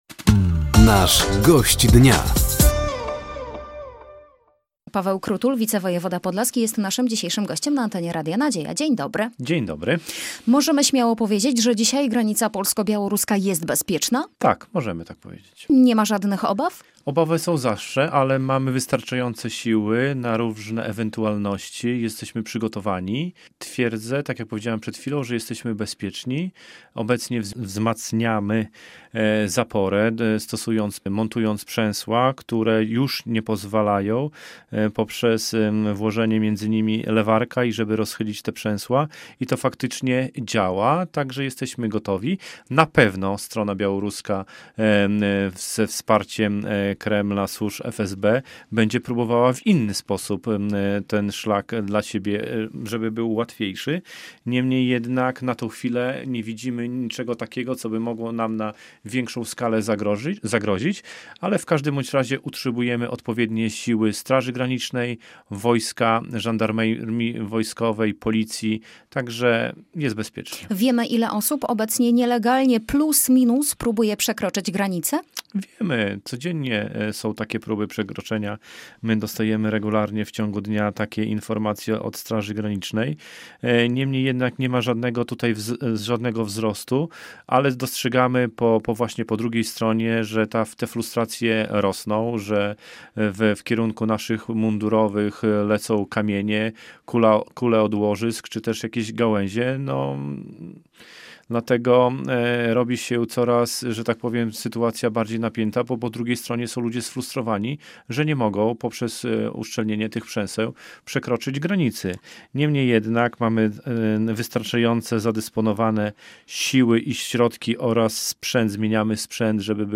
Gościem Dnia Radia Nadzieja był Paweł Krutul, wicewojewoda podlaski.